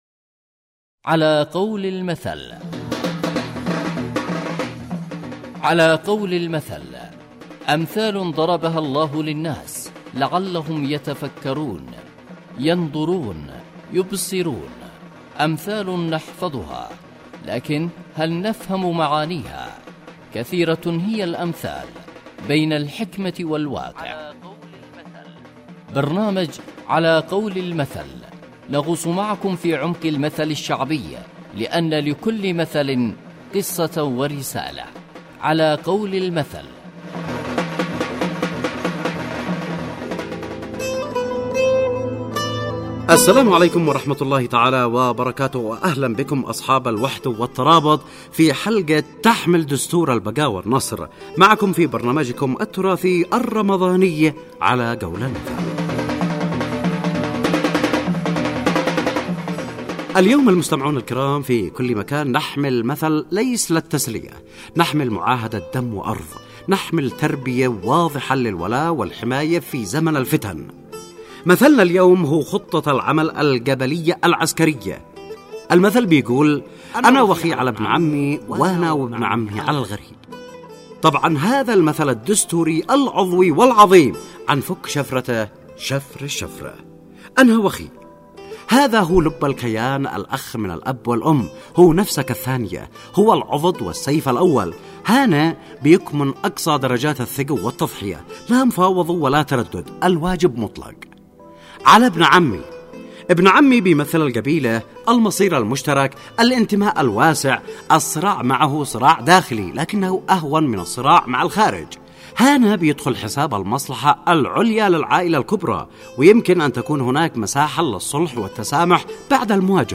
برنامج إذاعي يحكي عن معاني الامثال والحكمة منها كالمثل العسكري أو المثل المرتبط بأية قرآنية او المثل الشعبي . ويقدم تفسير للمثل والظروف التي أحاطت بالمثل وواقع المثل في حياتنا اليوم ويستهدف المجتمع.